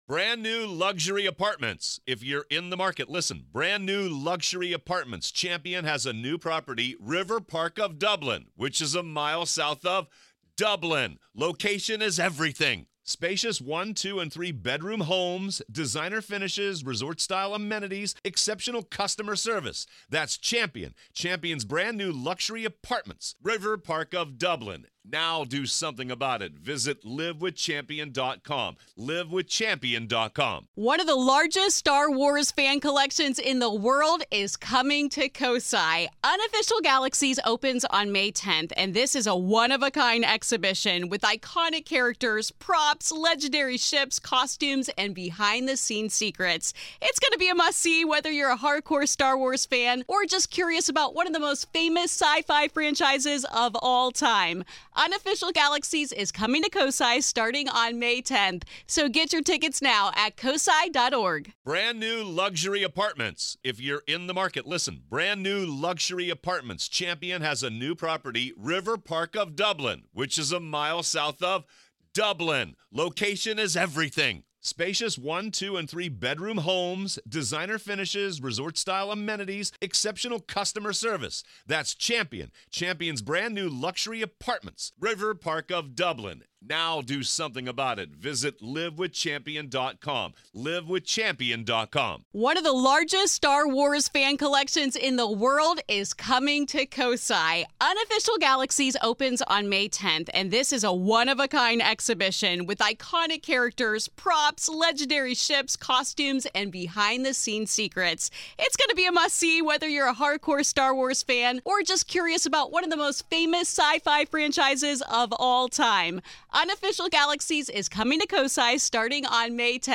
From the mess hall to the shower rooms, former staff, inmates, and investigators all report spine-tingling encounters with apparitions and unexplained phenomena. Today, Part One of our conversation about the hauntings of the Nevada State Prison